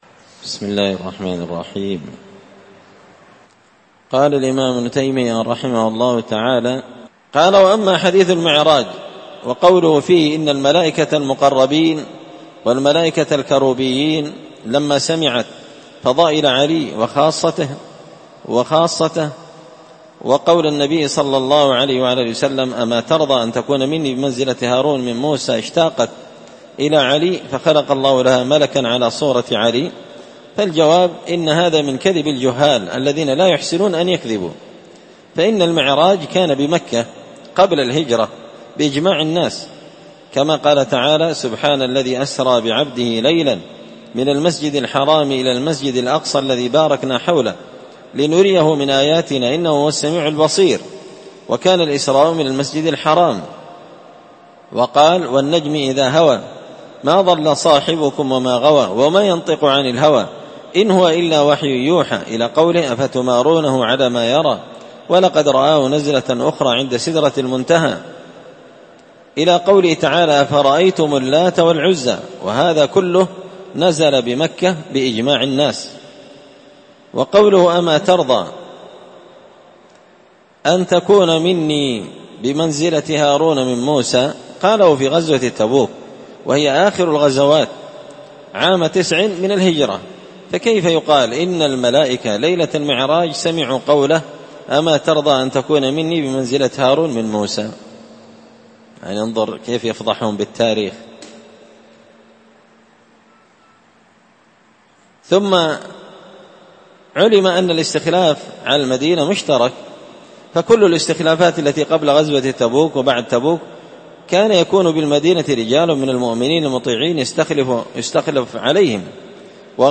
الأربعاء 18 ذو القعدة 1444 هــــ | الدروس، دروس الردود، مختصر منهاج السنة النبوية لشيخ الإسلام ابن تيمية | شارك بتعليقك | 7 المشاهدات
مسجد الفرقان قشن_المهرة_اليمن